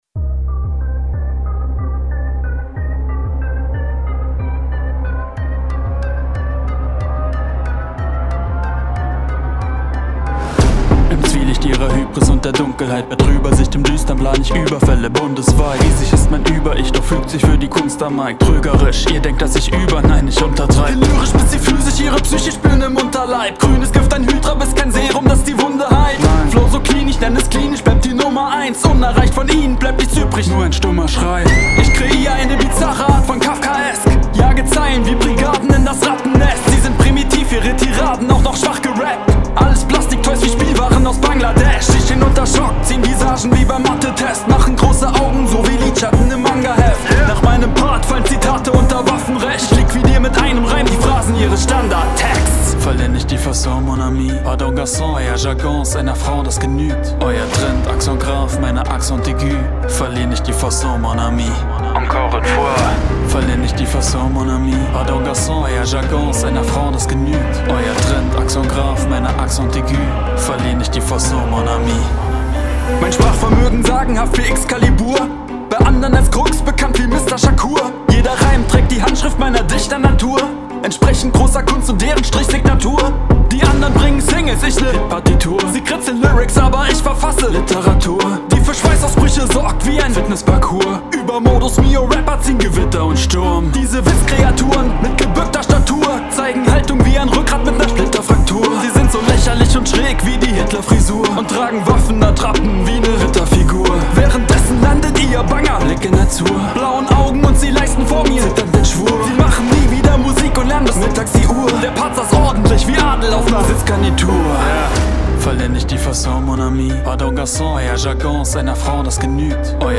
Sättigung/Saturation: Plugin für Vocals